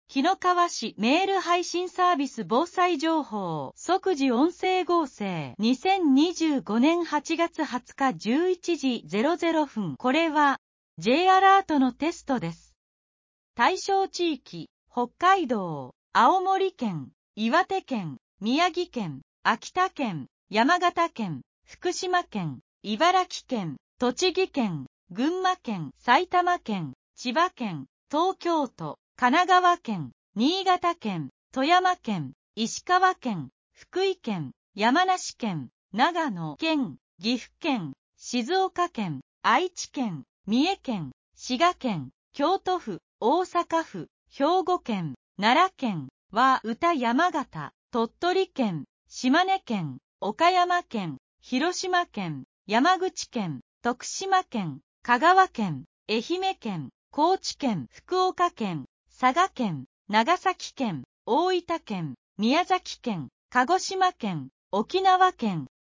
即時音声書換情報
紀の川市メール配信サービス【防災情報】 「即時音声合成」 2025年08月20日11時00分 これは、Jアラートのテストです。